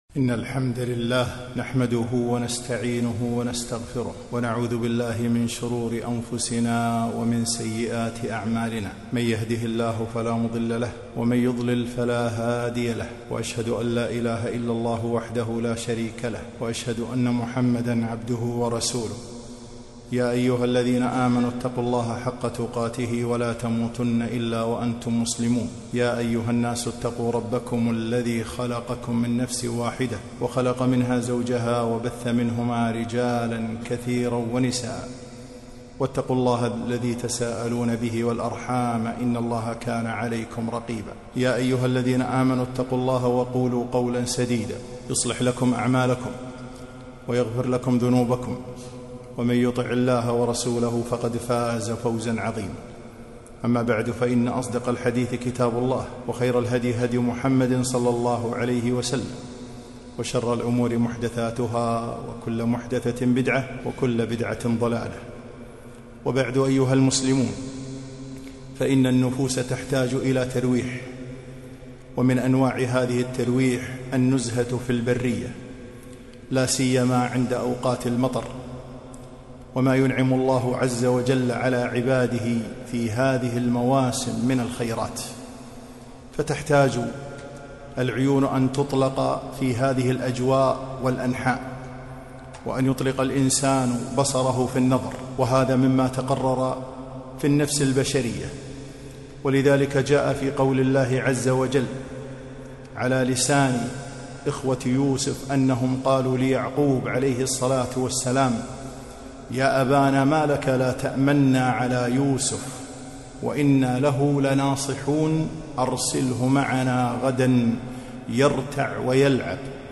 خطبة - التنزه والترويح عن النفوس